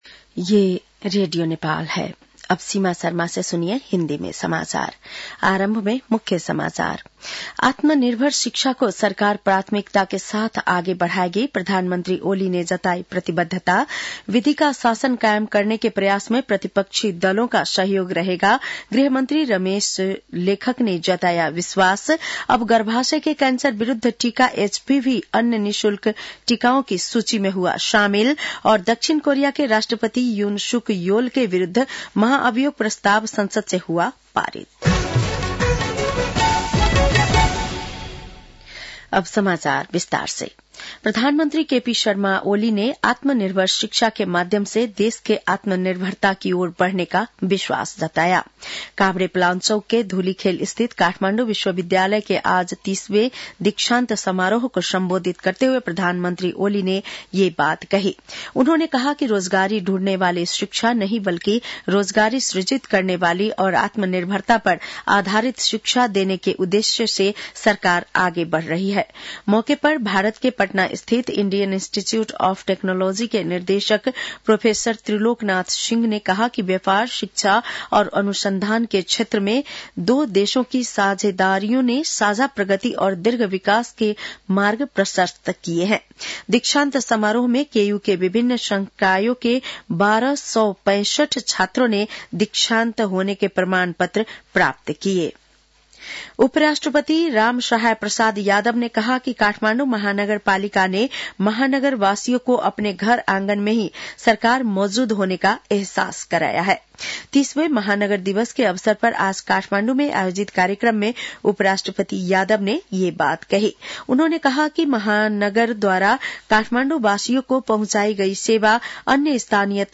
बेलुकी १० बजेको हिन्दी समाचार : ३० मंसिर , २०८१